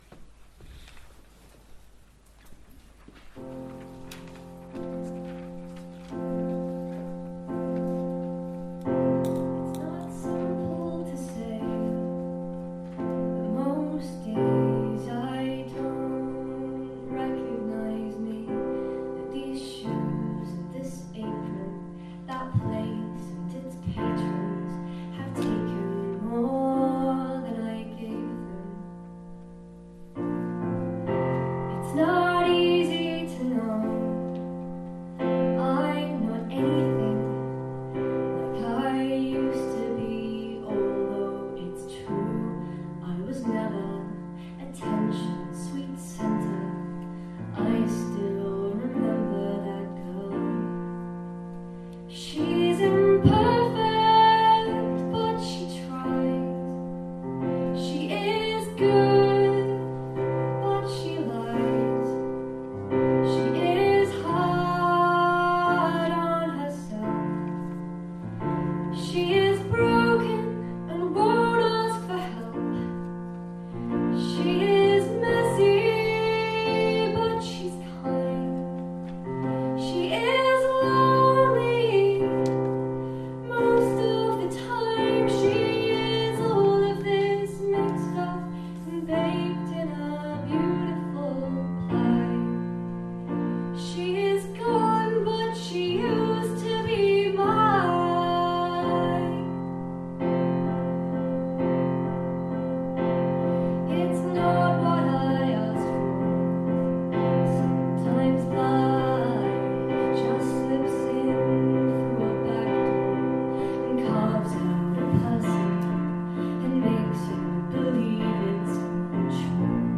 The whole evening, live and uncut.